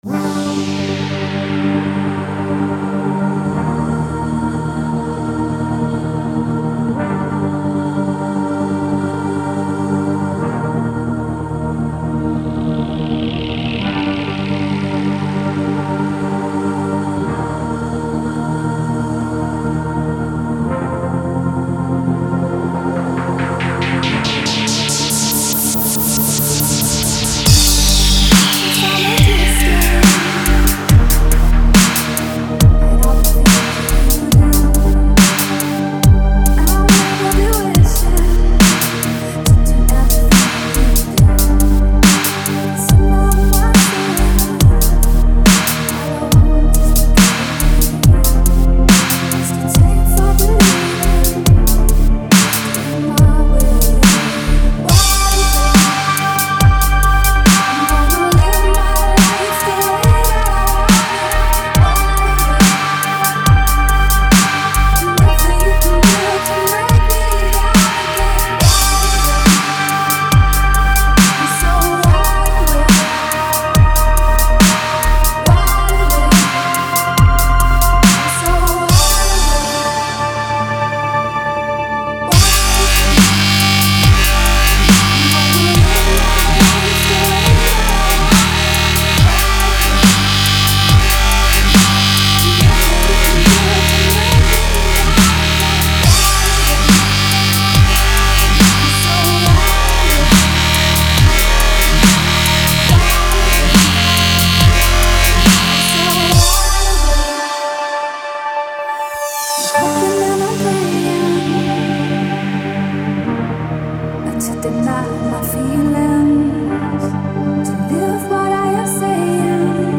DBS_s_zhenskim_vokalom_world79_spcs_bio.mp3